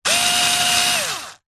Звуки шуруповёрта, мотор
Звук работающего шуруповерта